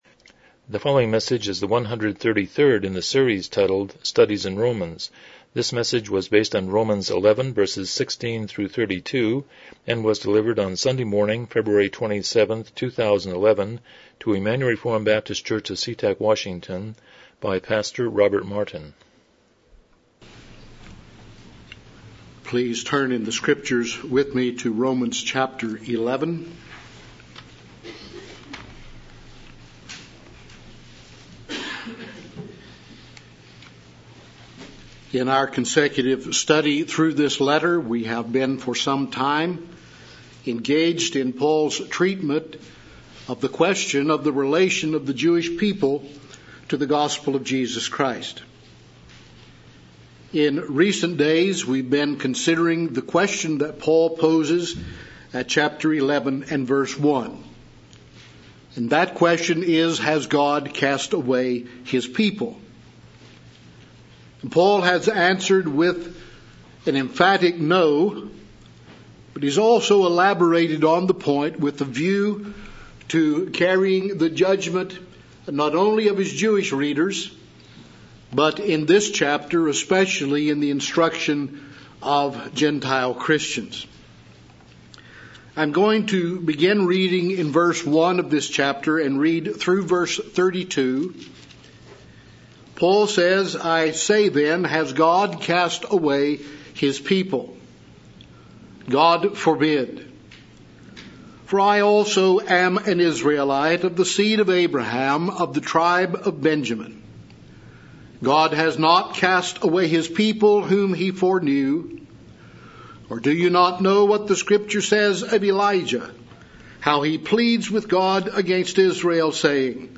Romans 11:16-32 Service Type: Morning Worship « 122 Chapter 24.1-2 Civil Magistrate